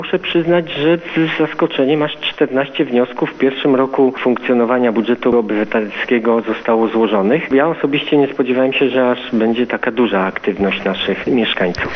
– To naprawdę wiele, zwłaszcza, że to pierwsza edycja – mówi zastępca burmistrza Pyrzyc Robert Betyna.